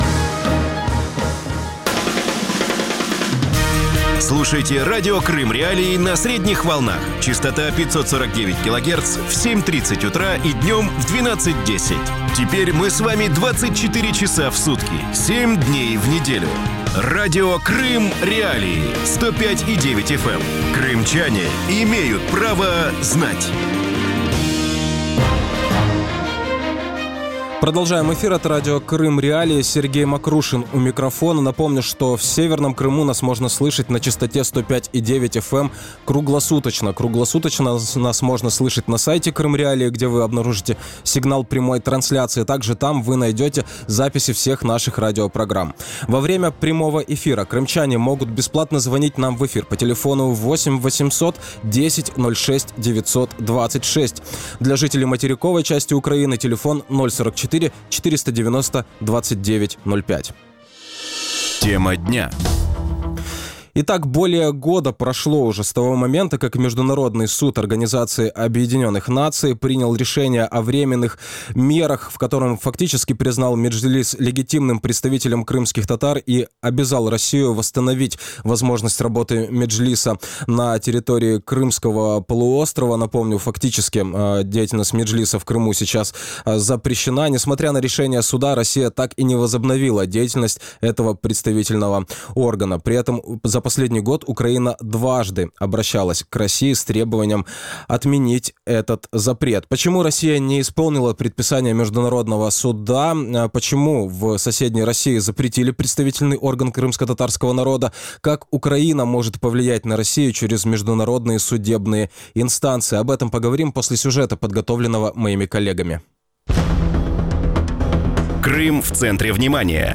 Радио Крым.Реалии в эфире 24 часа в сутки, 7 дней в неделю.